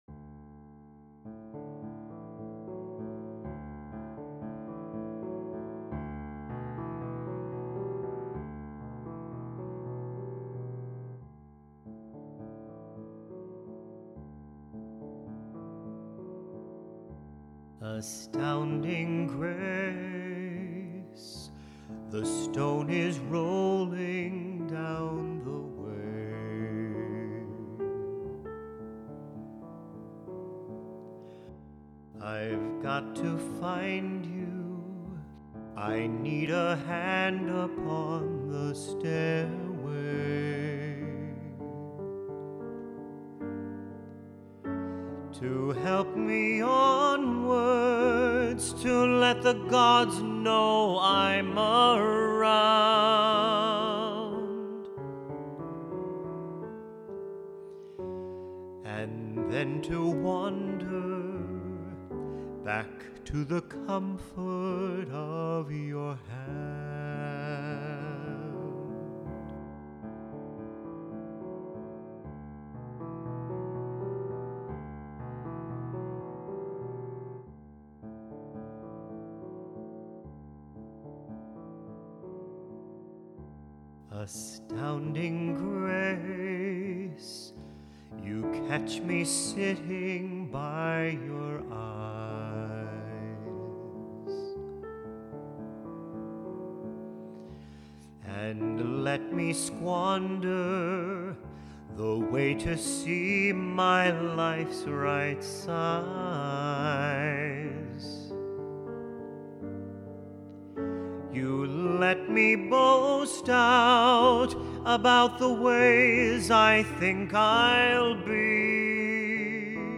Astounding Grace (Vocals